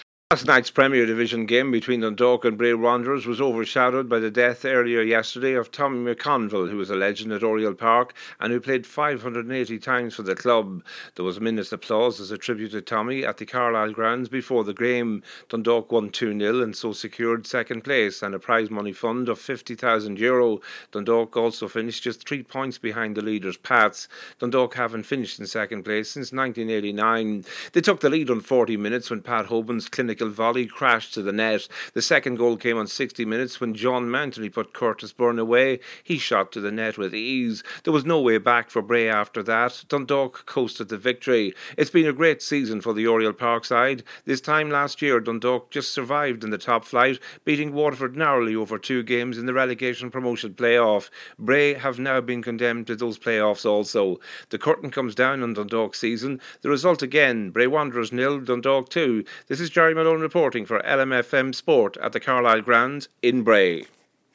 Dundalk full time report